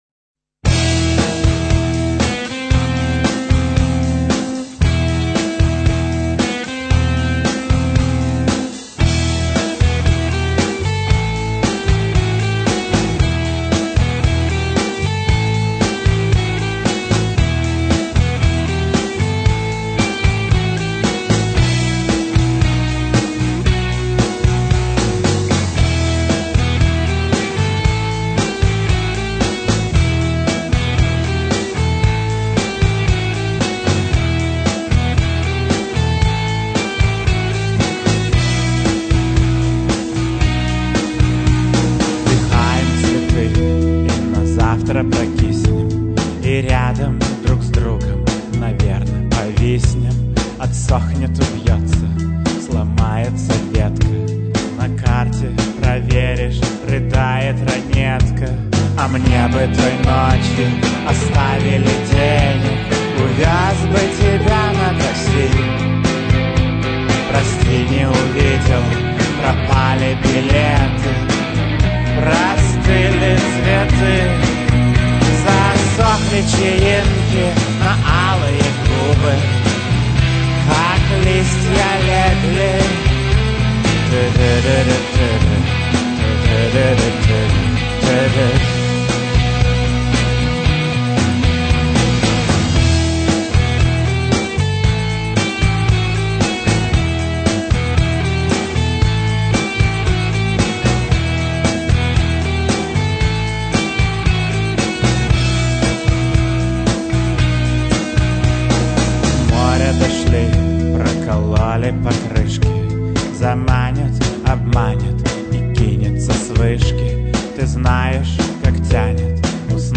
- клавишные
- guitars
- bass
- drums
- percussions
- saxophone
- piano
- backing vocal
- голос